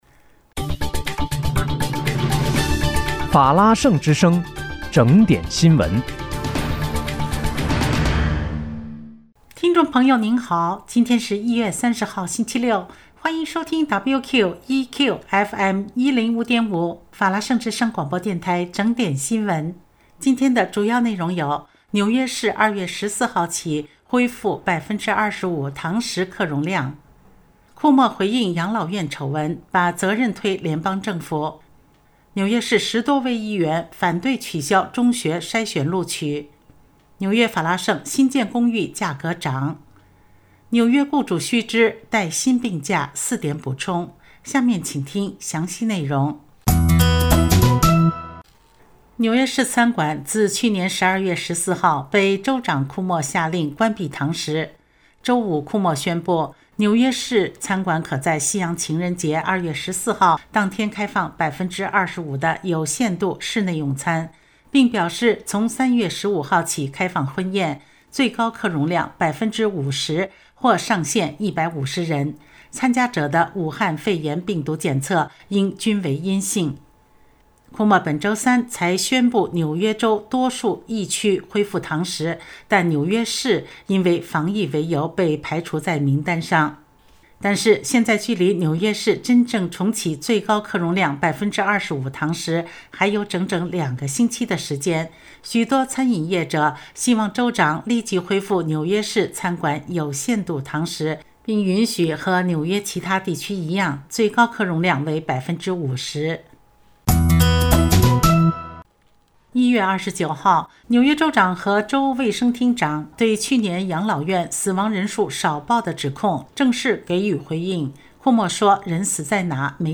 1月30日（星期六）纽约整点新闻